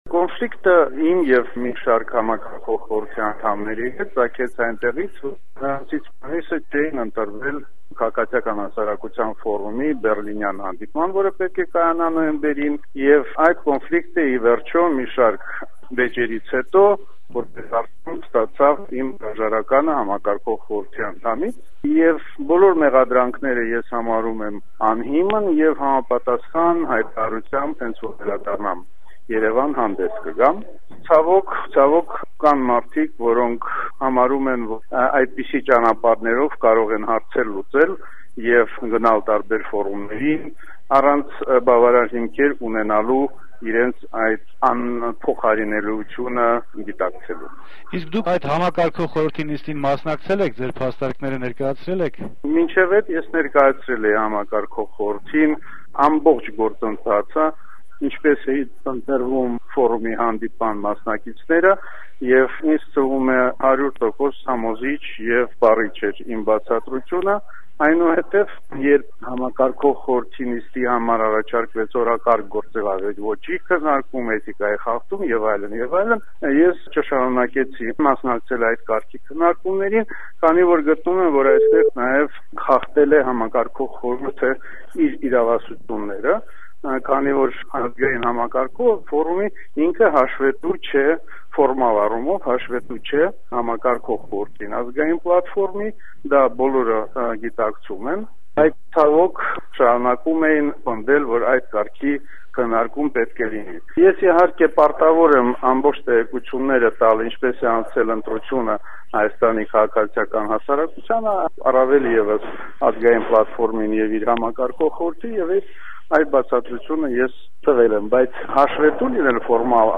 Հարցազրույց